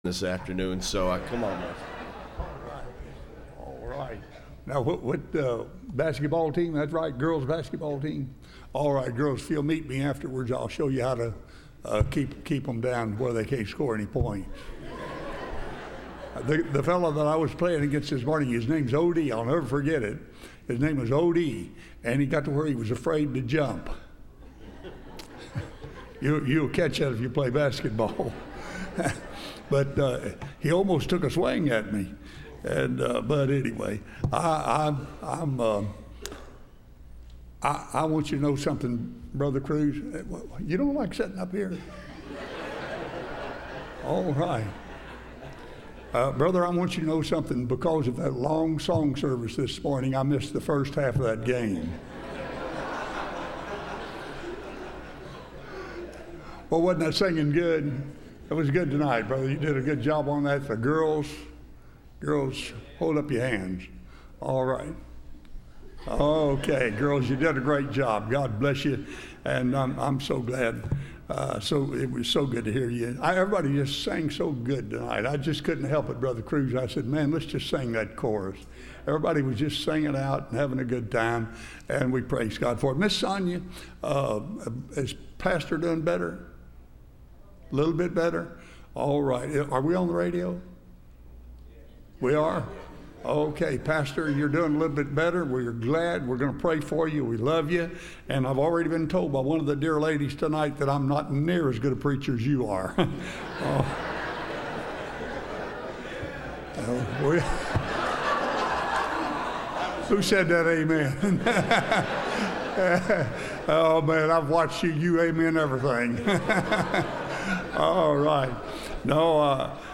Service Type: Missions Conference